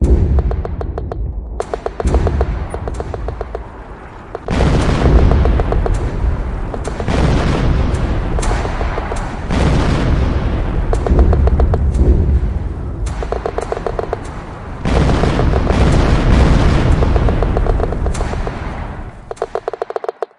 military sounds » Real War Marines in Firefight Gunbattle (Raw audio)
标签： firefight grenade shots officer command guns fighting gun shotgun training shoot desert rifle background taliban gunbattle siege war pistol ambience fire machine army occupation soldiers battle us canon shot pistols
声道立体声